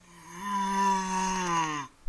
生物的声音 " 亡灵的呻吟 3
描述：模仿僵尸/亡灵呻吟。
Tag: 怪物 僵尸 僵尸 恐怖 怪物 僵尸